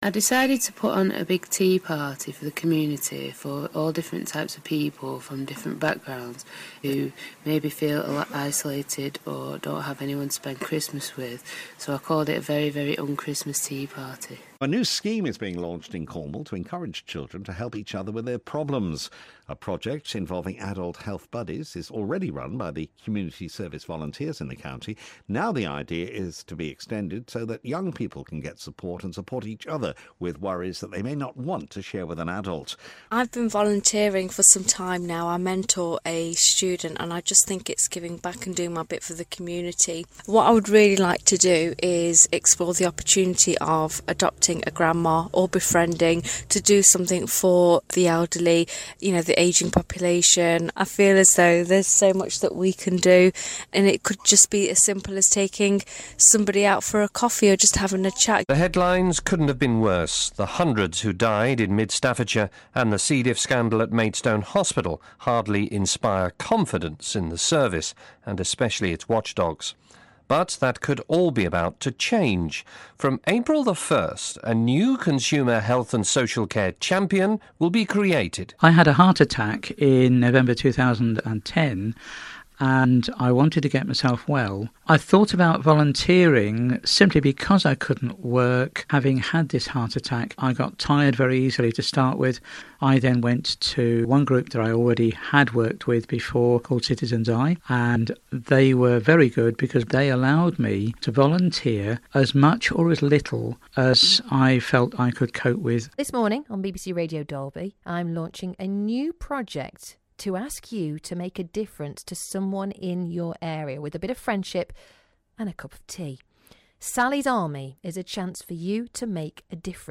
CSV’s Action Network raises awareness of issues from mental health to local history through its link with BBC Local radio. Here is a snapshot of activity on air in 2013.